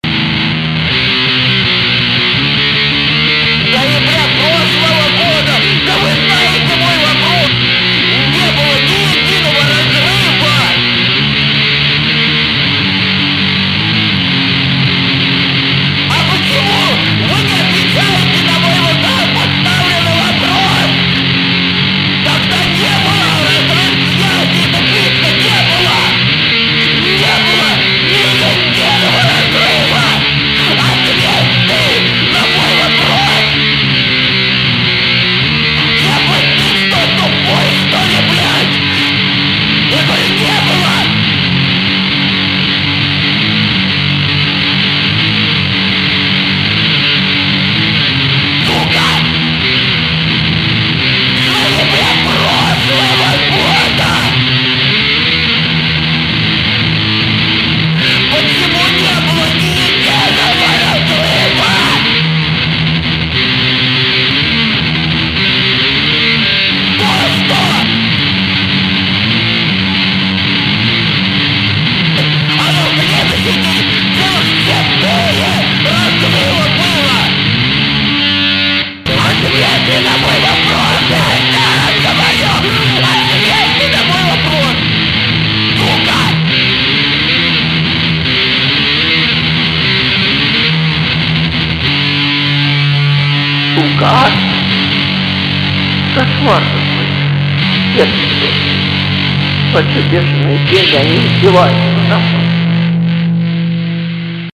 ������� ���������� �������� Death Metal.������������ ����� - �� ������ ������� ����...